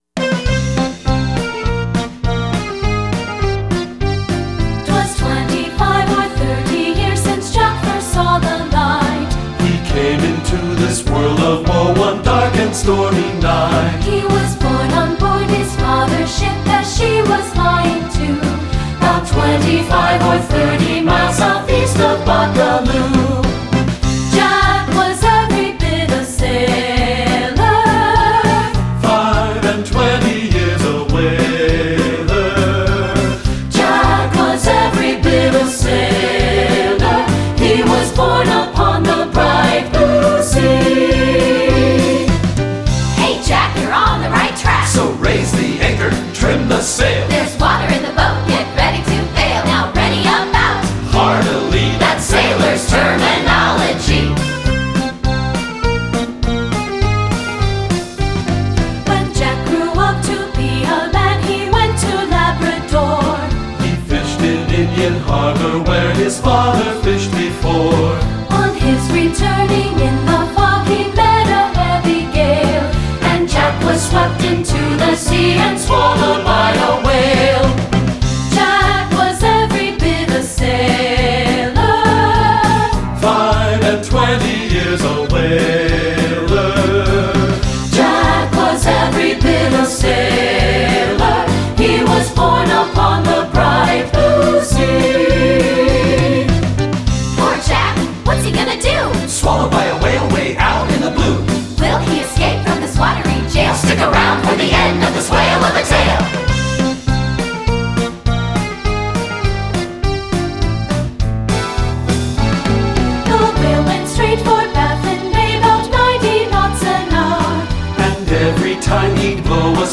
Choral Light Concert/Novelty
Newfoundland Folk Song
SAB